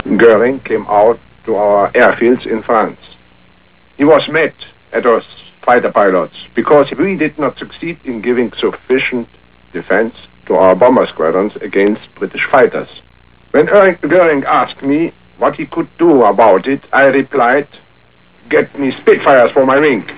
Click Galland's image to the right to hear him tell about it.